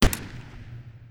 SMG1_Shoot 01.wav